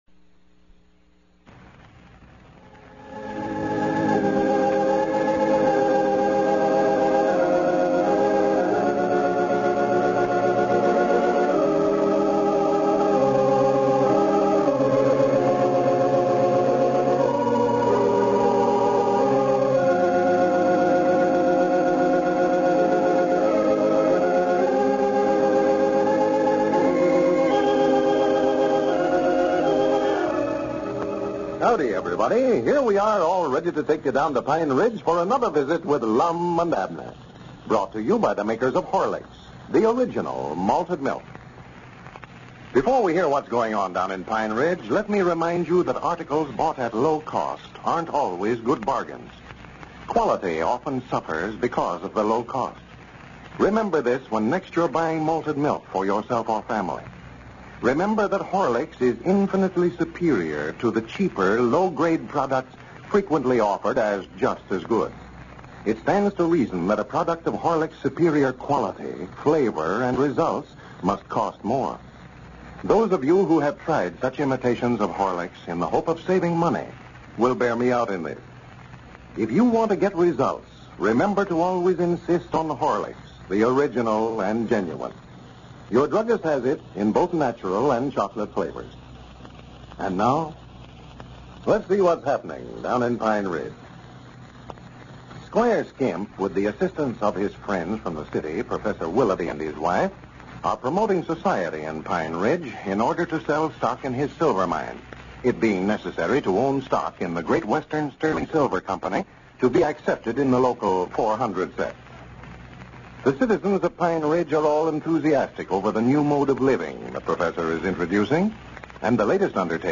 A classic radio show that brought laughter to millions of Americans from 1931 to 1954.